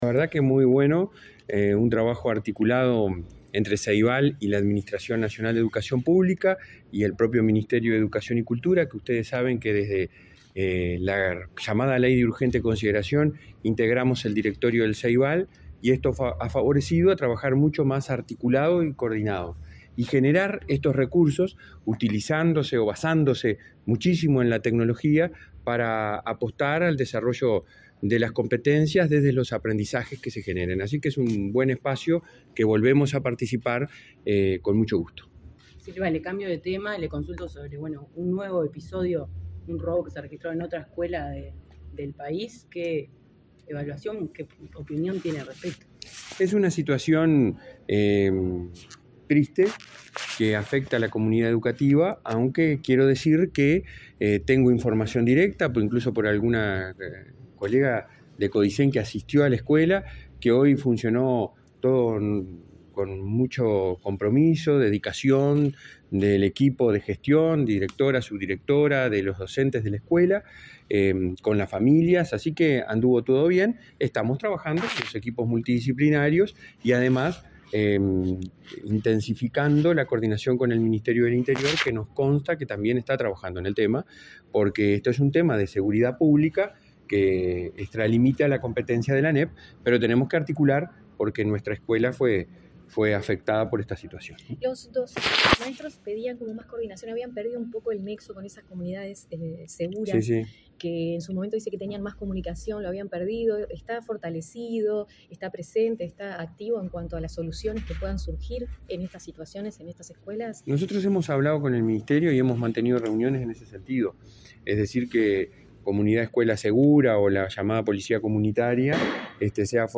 Declaraciones del presidente de la ANEP, Robert Silva
Declaraciones del presidente de la ANEP, Robert Silva 14/06/2023 Compartir Facebook X Copiar enlace WhatsApp LinkedIn Ceibal y la Administración Nacional de Educación Pública (ANEP) lanzaron, este miércoles 14 en el auditorio del Sodre, la tercera temporada de la serie Aprendices. Luego, el presidente de la ANEP, Robert Silva, dialogó con la prensa.